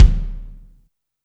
INSKICK17 -R.wav